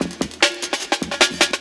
61 LOOP02.wav